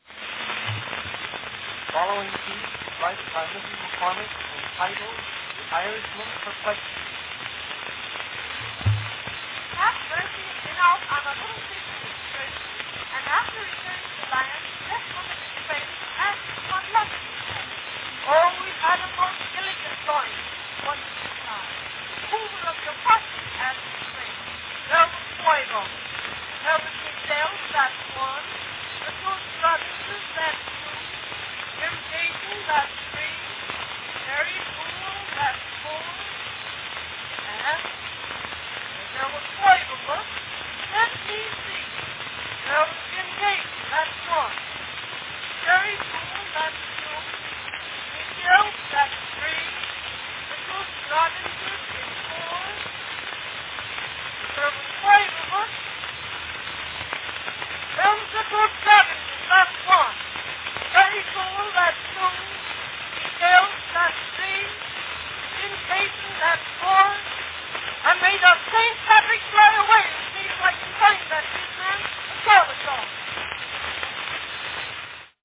Company New Jersey Phonograph Company (?)
Category Specialty (comic duologue)
— This cylinder of the month is from the collection of  The Library of Congress —